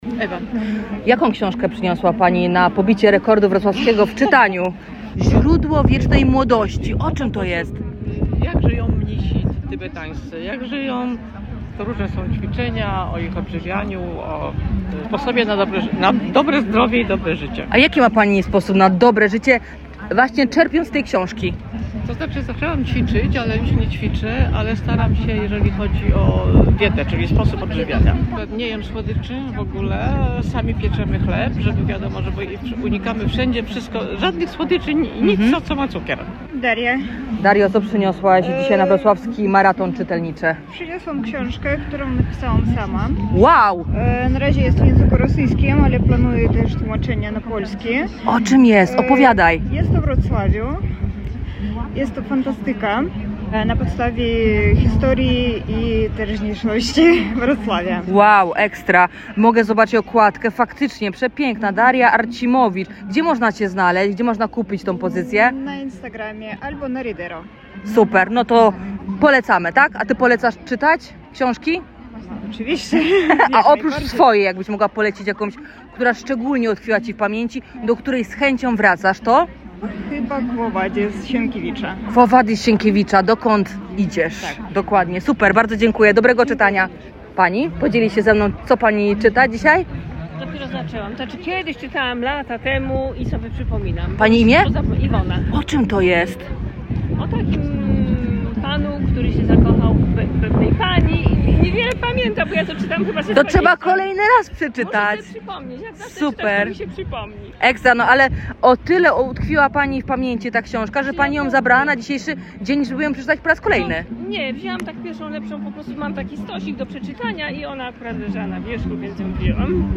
23 kwietnia w Międzynarodowym Dniu Książki i Praw Autorskich miłośnicy książki spotkali się na wrocławskim placu Solnym, by wspólnie pobić rekord w czytaniu.
Miłośnicy książek mogli także opowiedzieć o swojej książce na scenie, ale w Radiu Rodzina.